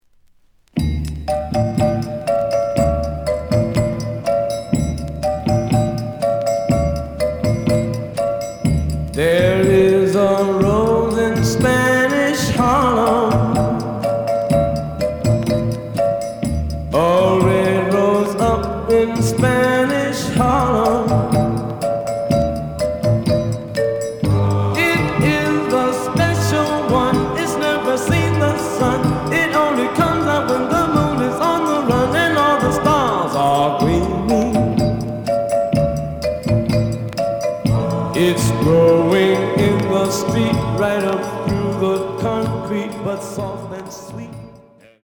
The audio sample is recorded from the actual item.
●Genre: Soul, 60's Soul
Some click noise on B side due to scratches.